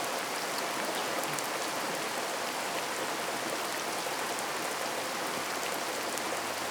tenkoku_rain.wav